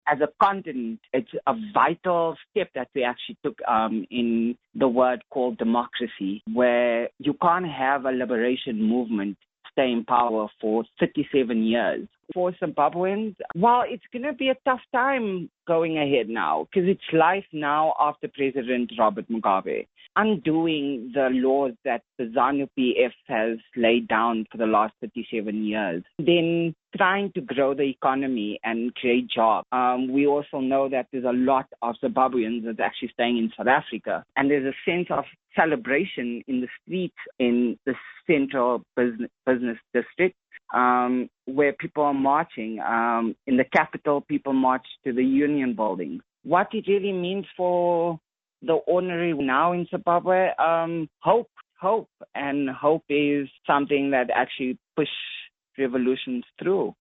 Comentariu în engleză: